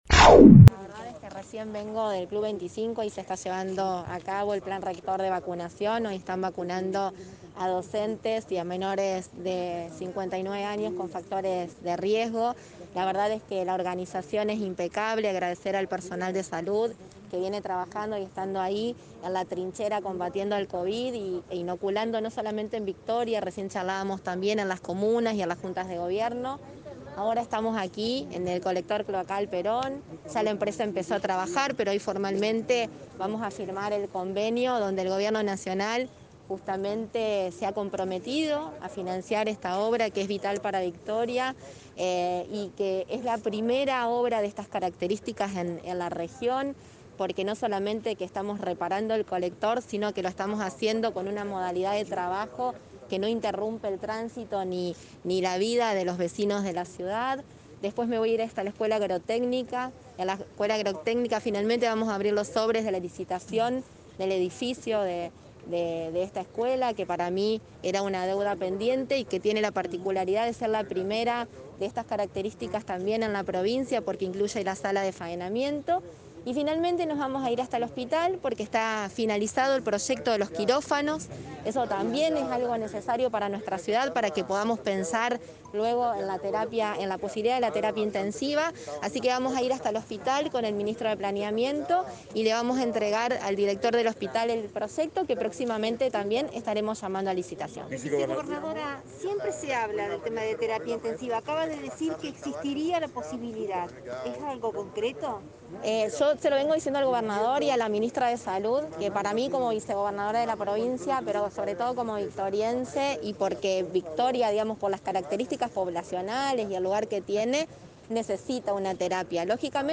Conferencia Laura Stratta